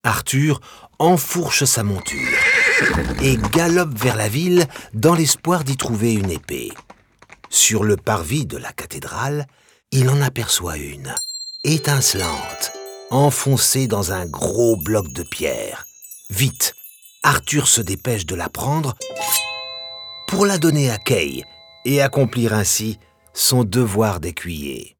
Une histoire à écouter, enregistrement et direction du comédien, réalisation du sound design adapté au support puce électronique.